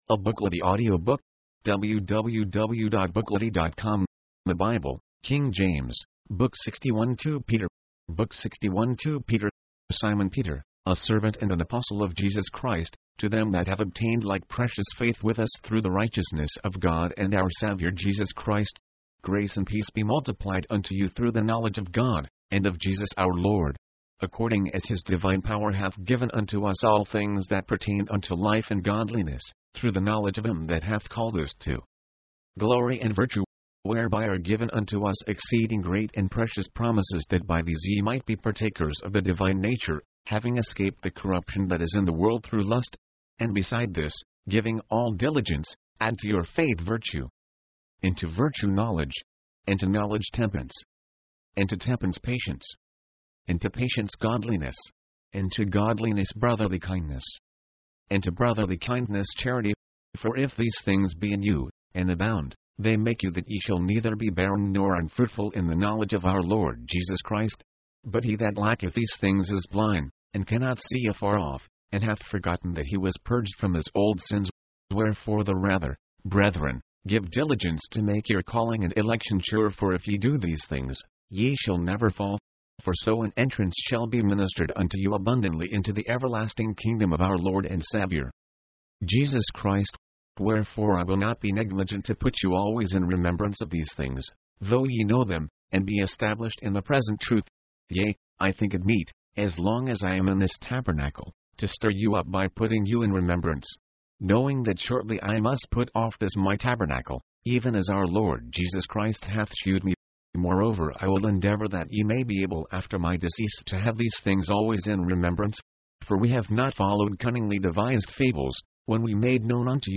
His delay in returning allows people time to repent. mp3, audiobook, audio, book